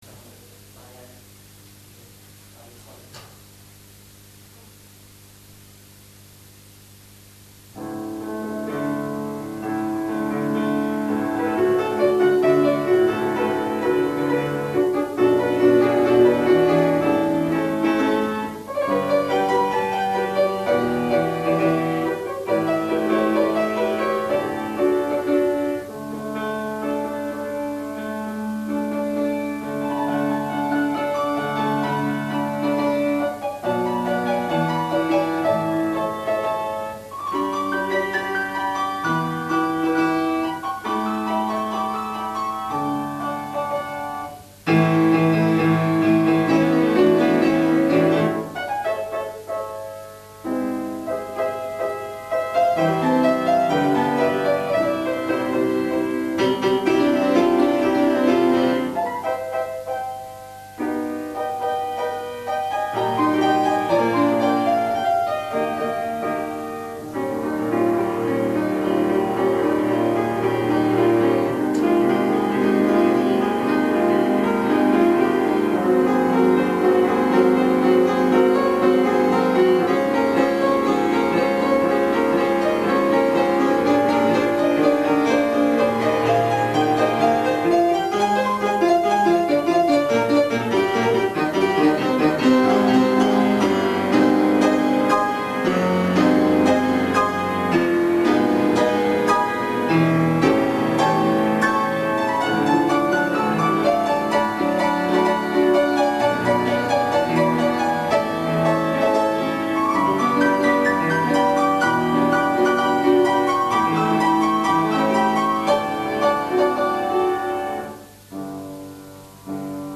Mes morceaux de piano